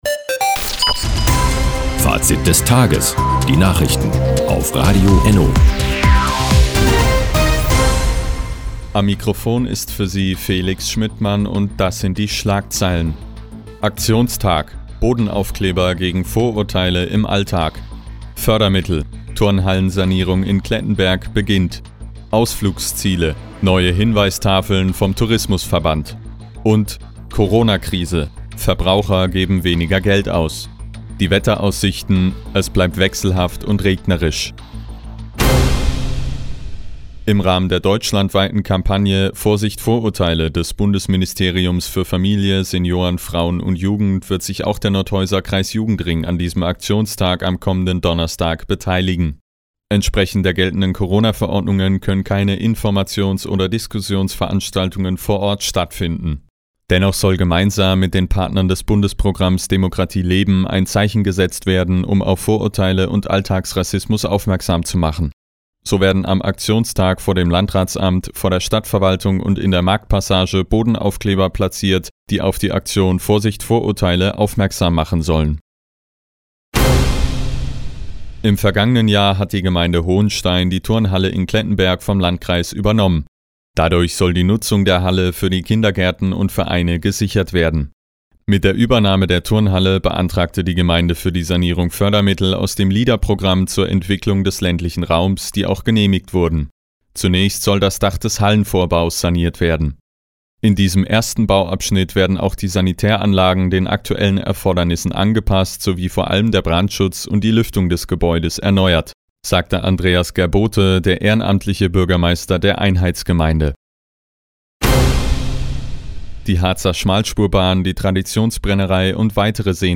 Mo, 16:30 Uhr 15.03.2021 Neues von Radio ENNO Fazit des Tages Anzeige symplr (1) Seit Jahren kooperieren die Nordthüringer Online-Zeitungen und das Nordhäuser Bürgerradio ENNO. Die tägliche Nachrichtensendung ist jetzt hier zu hören.